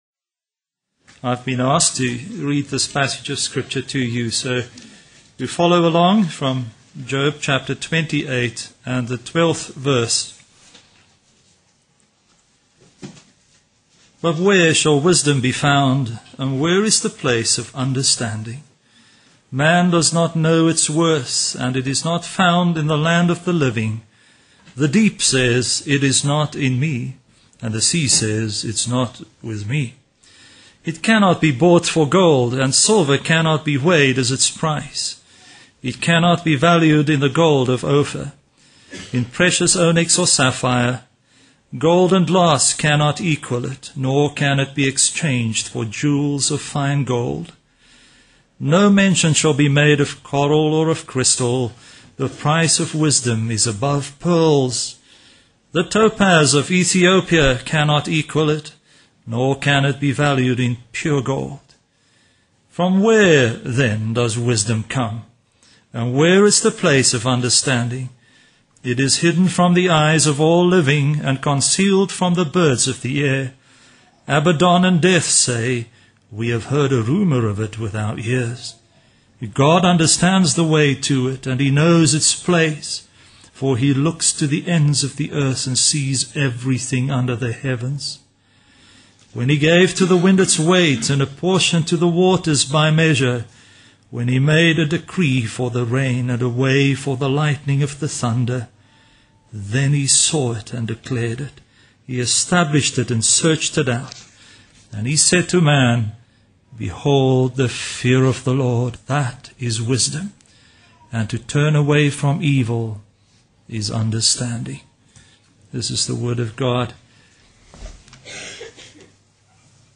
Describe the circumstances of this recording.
Grace Ministers' Conference 2011